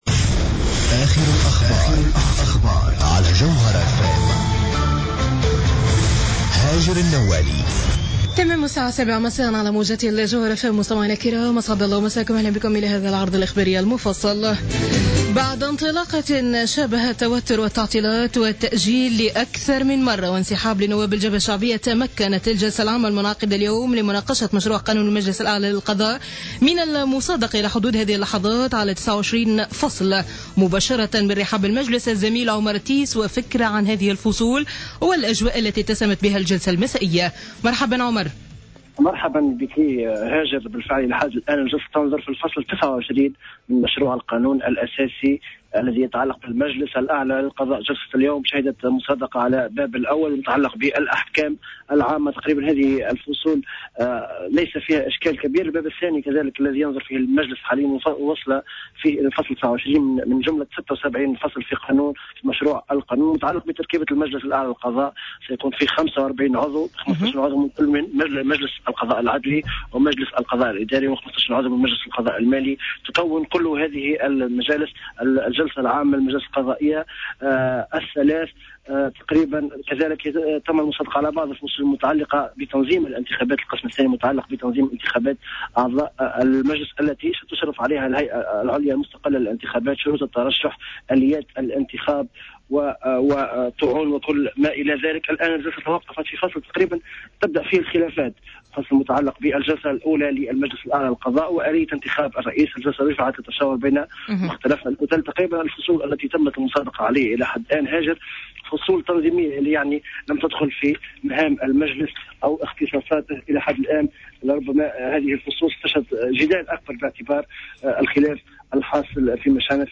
نشرة أخبار السابعة مساء ليوم الأربعاء 13 ماي 2015